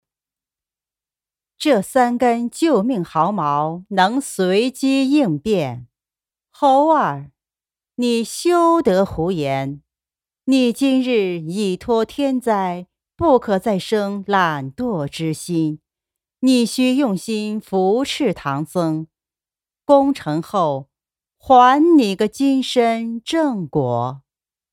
16 女国145_动画_模仿_模仿老版观音菩萨 女国145
女国145_动画_模仿_模仿老版观音菩萨.mp3